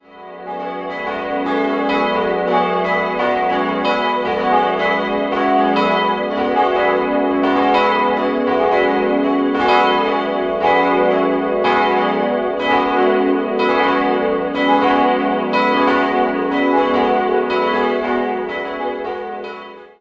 4-stimmiges Gloria-TeDeum-Geläute: fis'-gis'-h'-cis'' Die Glocken 1-3 wurden 1996 von Rudolf Perner in Passau gegossen, die kleine historische Glocke stammt noch aus dem 14.